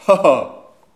Les sons ont été découpés en morceaux exploitables. 2017-04-10 17:58:57 +02:00 168 KiB Raw History
ah-ah_01.wav